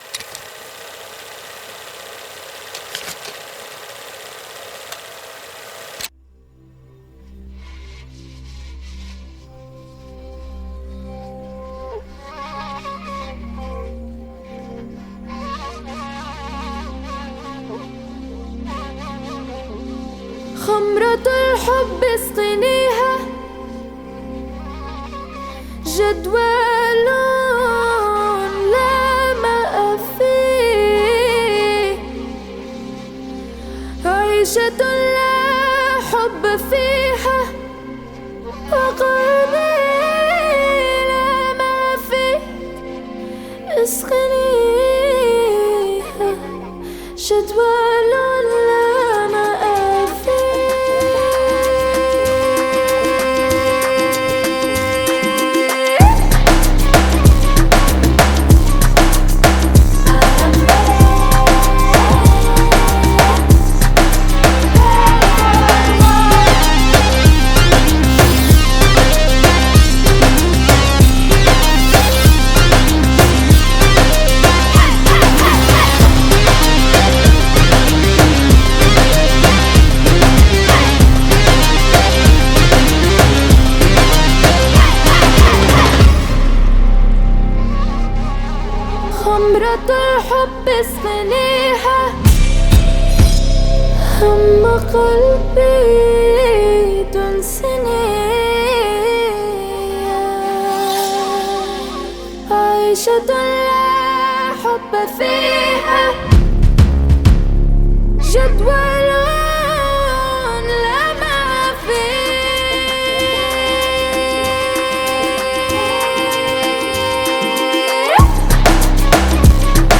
Жанр: Русская музыка